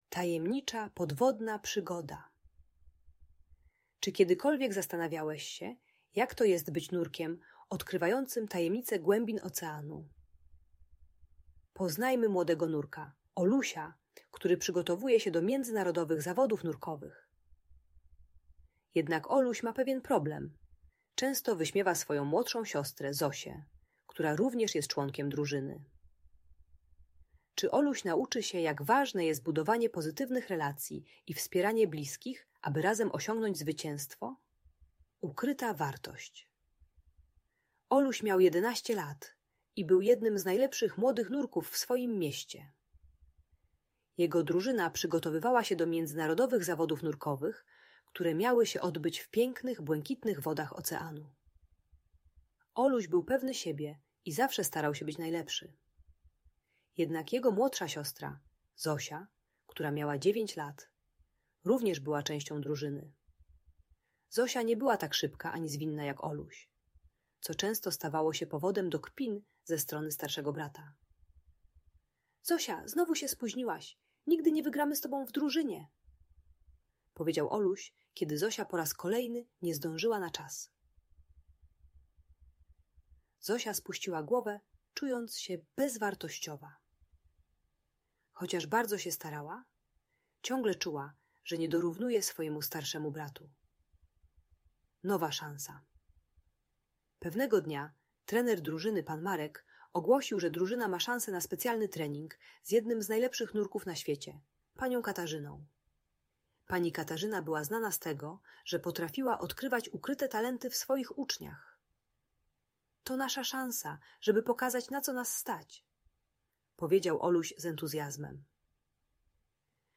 Tajemnicza Podwodna Przygoda - historia o przyjaźni i współpracy - Audiobajka